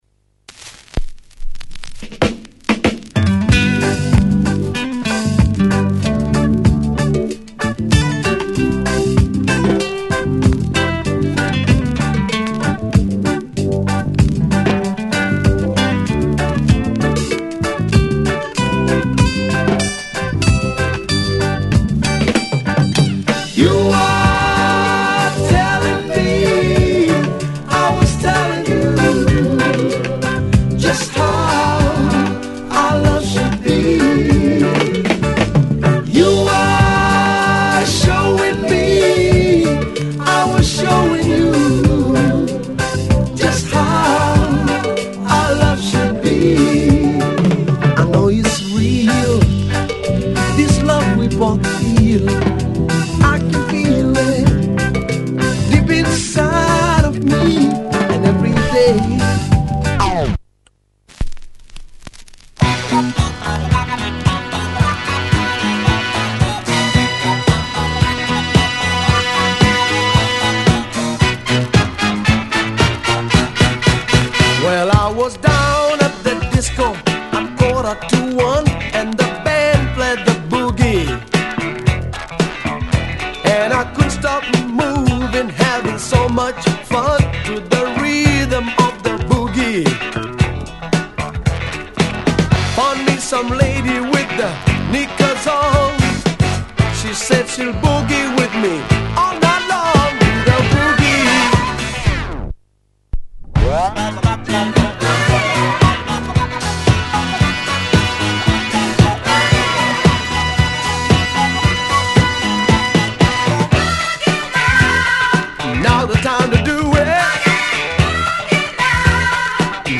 Slightly Reggae fused number
disco